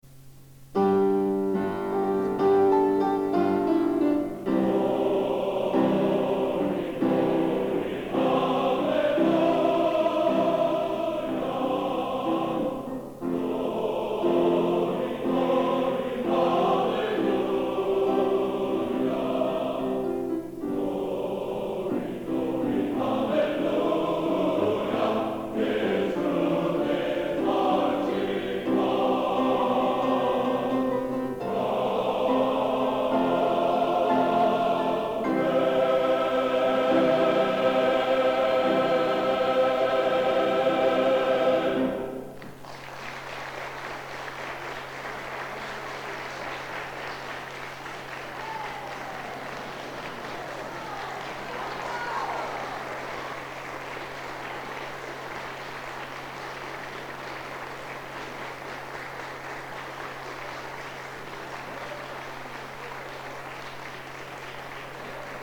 Collection: Stockholm, 1987
Location: Old Academy of Music, Stockholm, Sweden